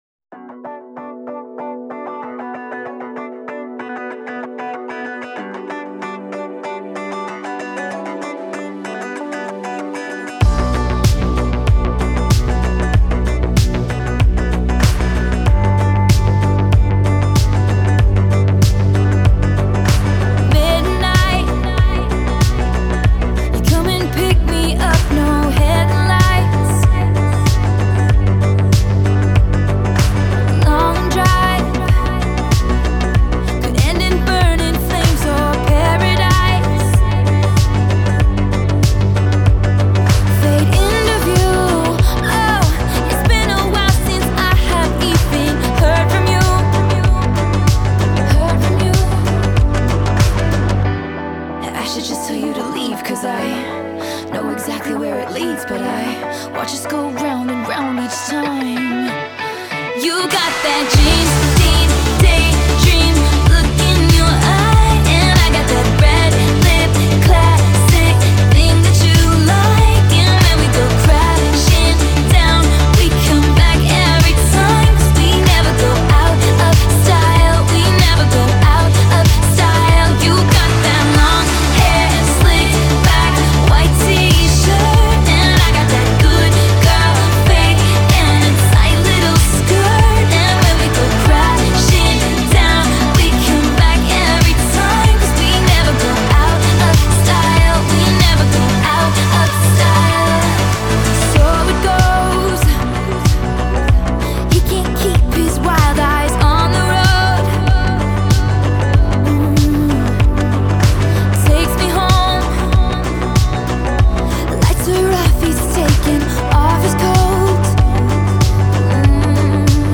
ژانر: پاپ / راک